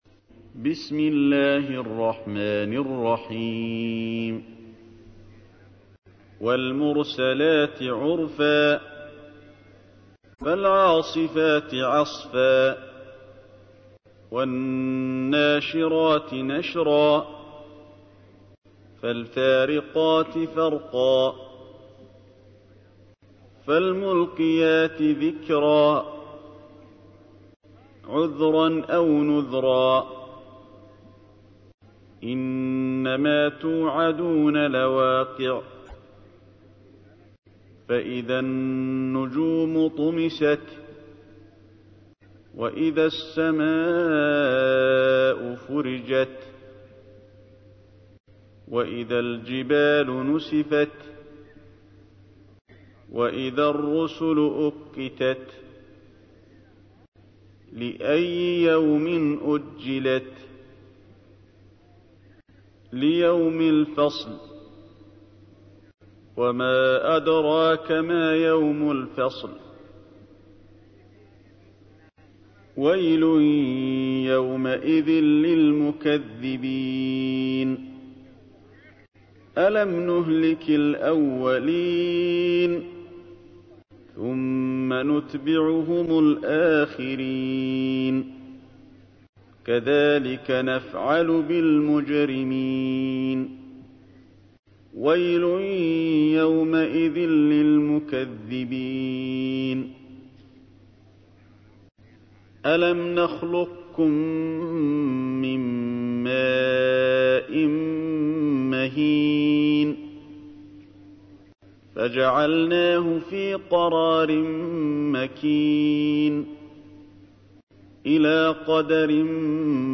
تحميل : 77. سورة المرسلات / القارئ علي الحذيفي / القرآن الكريم / موقع يا حسين